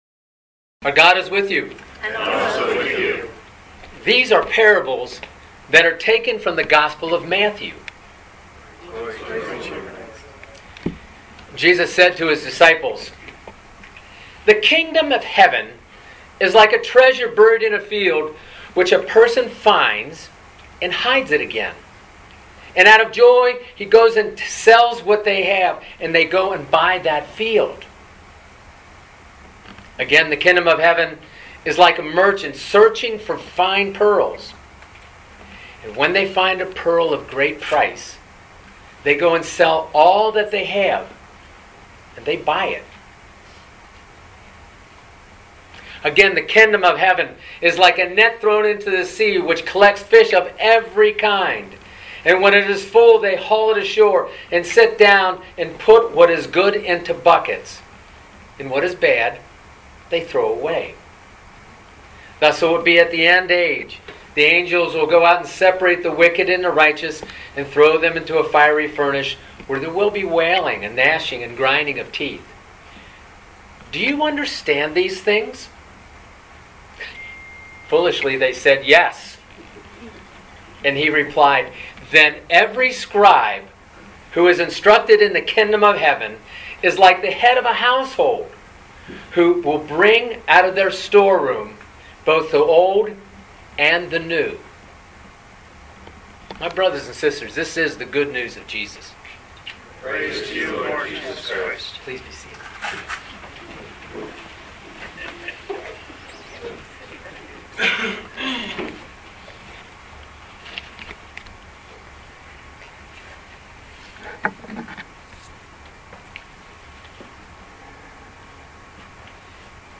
Living Beatitudes Community Homilies: Grace